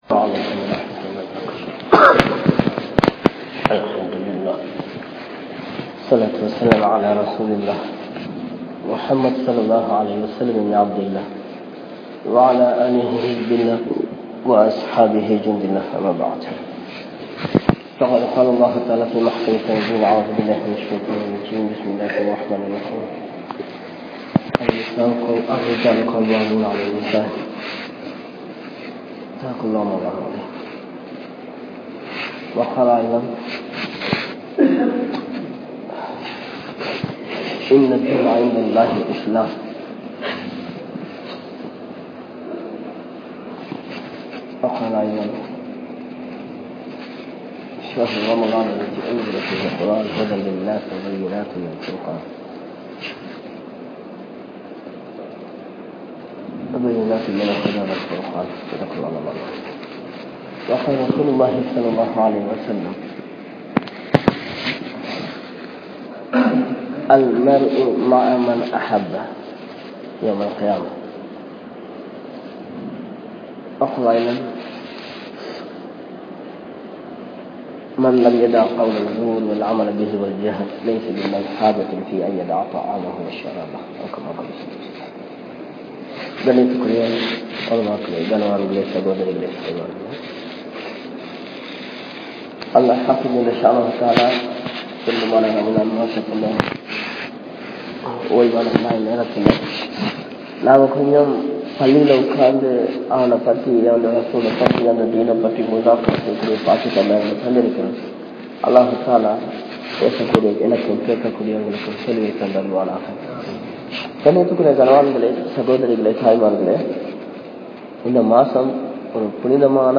Paavaththiiku Kaaranamaana Pengal (பாவத்திற்கு காரணமான பெண்கள்) | Audio Bayans | All Ceylon Muslim Youth Community | Addalaichenai
Masjithun Noor Jumua Masjidh